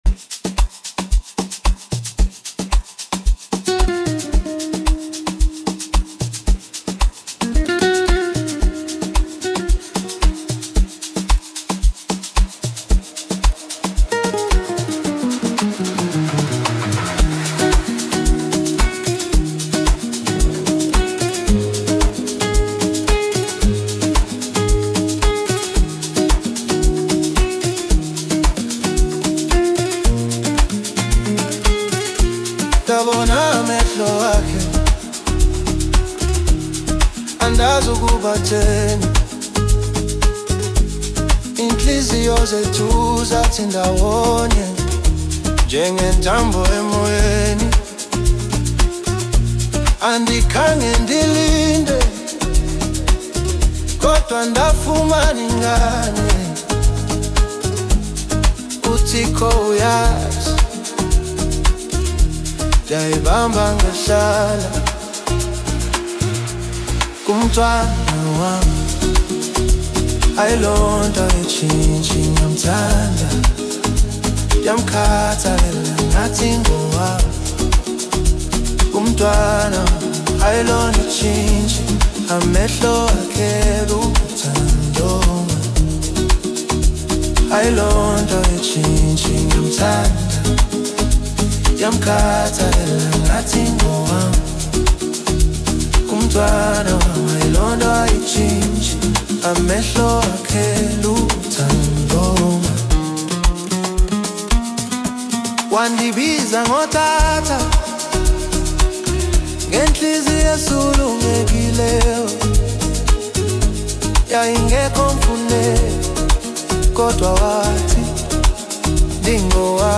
Home » Amapiano
South African singer